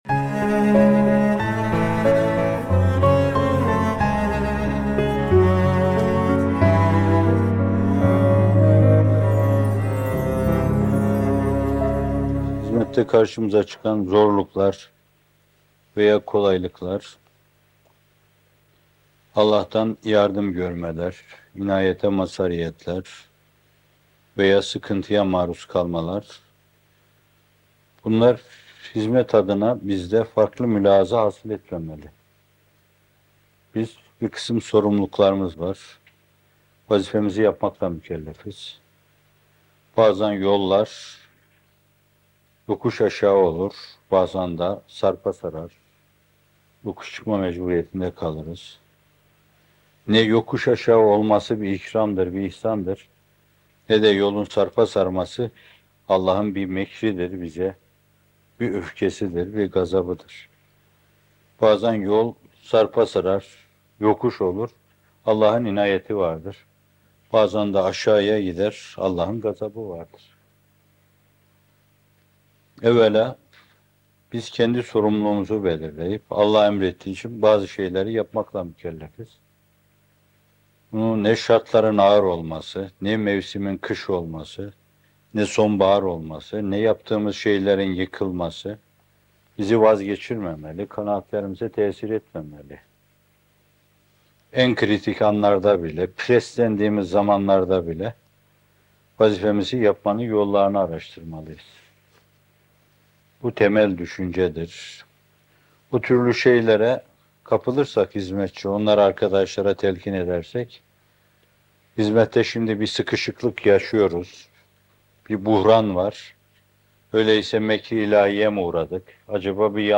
Hizmette Karşılaşılan Zorluklar ve Tekâmül - Fethullah Gülen Hocaefendi'nin Sohbetleri